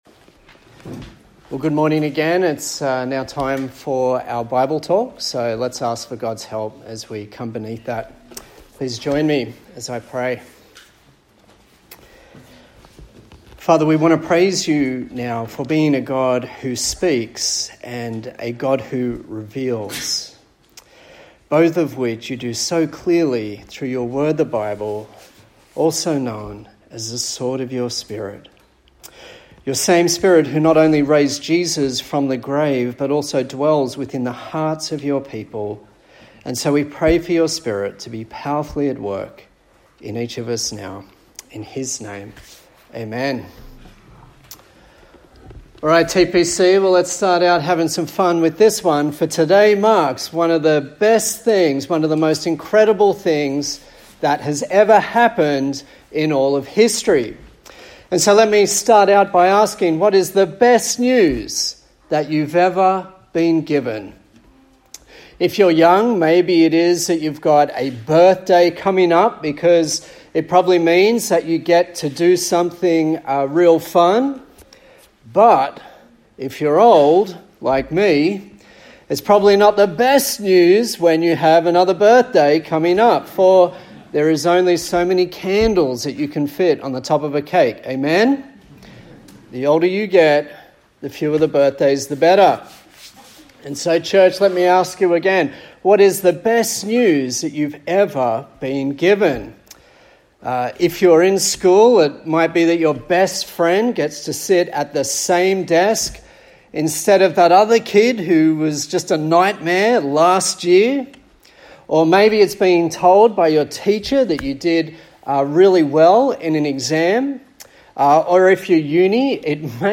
A sermon for Resurrection Sunday on the book of 1 Corinthians